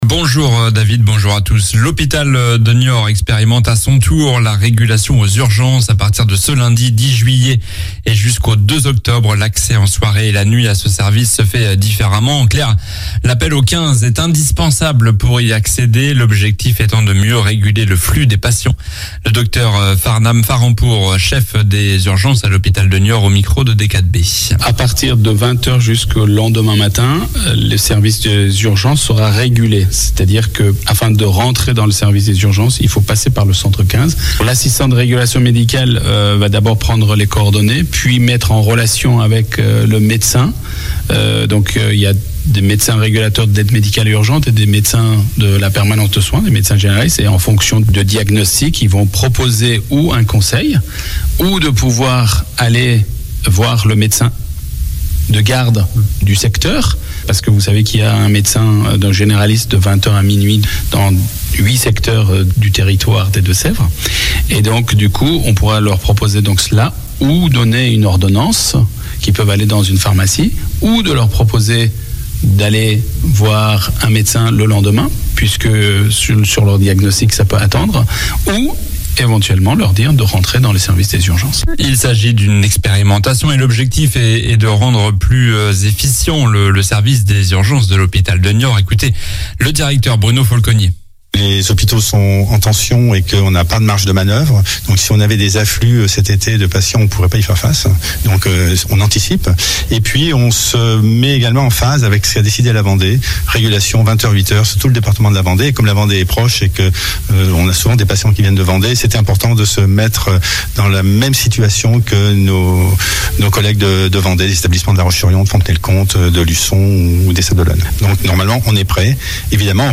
Journal du lundi 10 juillet (matin)